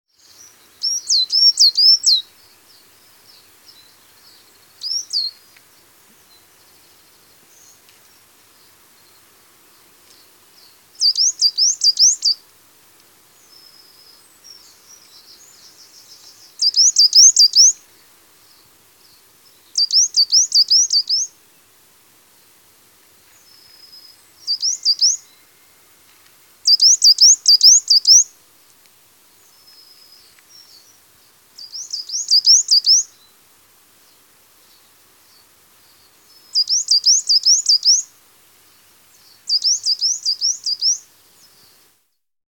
Coal tit call
Listen to the call of the coal tit to help you identify this common garden bird during your birdwatch.